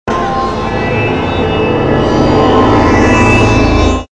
cloak_rh_gunboat.wav